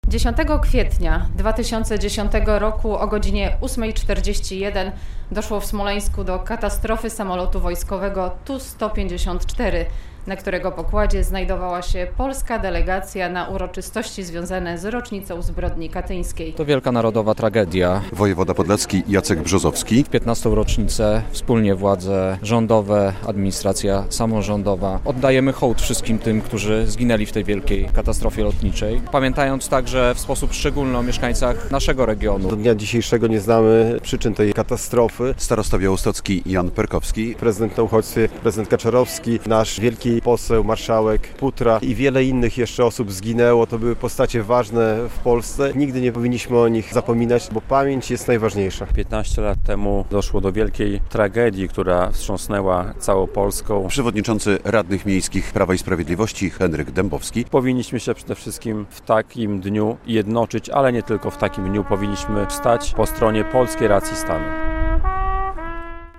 Obchody 15. rocznicy tragicznej katastrofy lotniczej pod Smoleńskiem w Białymstoku - relacja